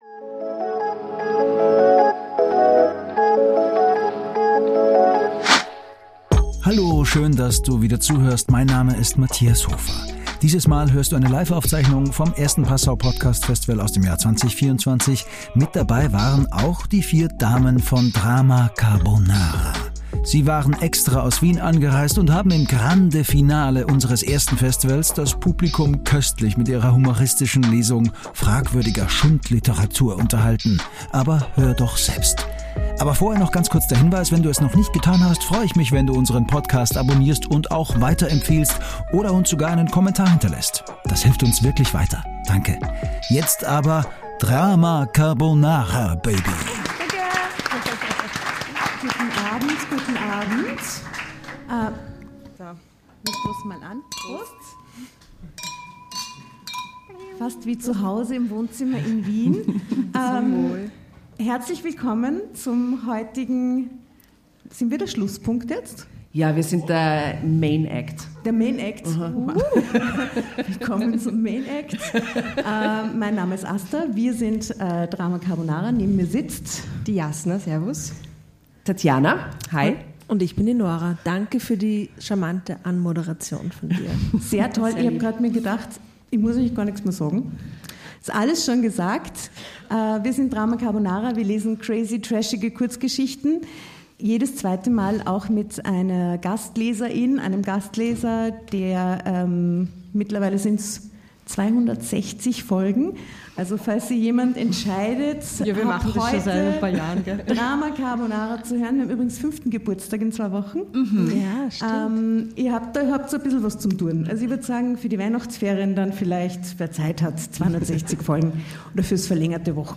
Die vier Damen von Drama Carbonara waren extra aus Wien angereist und haben im Grande Finale unseres ersten Passau Podcast Festivals das Publikum köstlich mit ihrer humoristischen Lesung fragwürdiger Schundliteratur unterhalten.